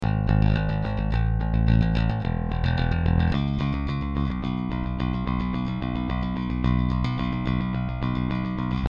Akorát jsem se dostal ke stopám basy, tak tu dávám pro porovnání kousek, rozdíl je tam (mezi mikrofony a linkou - která je ale PRE-EQ, což to porovnání s linkou trochu kazí).
Line Out - PRE EQ